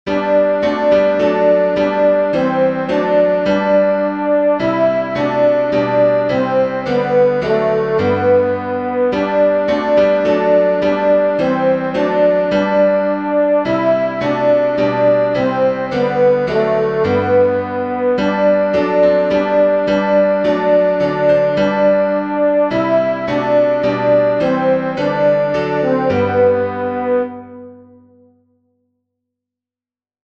Alto
dix_as_with_gladness_men_of_old-alto.mp3